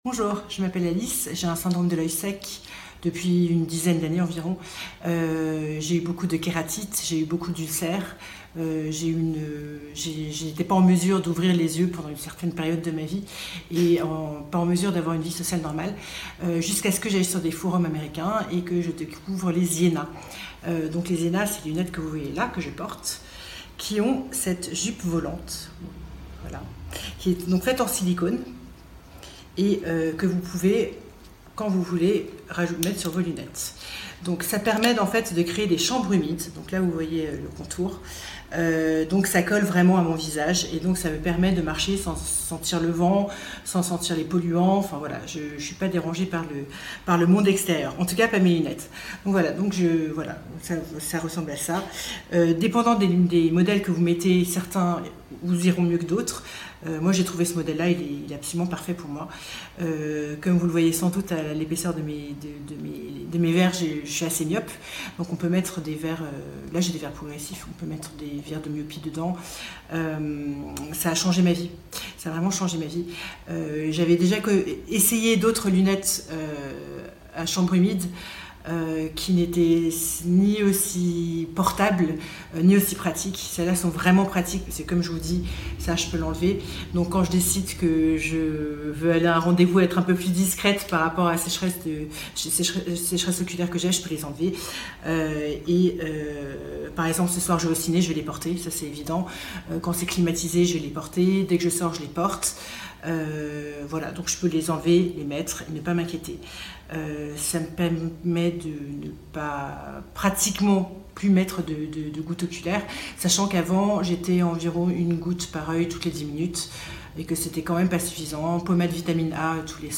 temoignage-lunette-chambre-humide-zena.mp3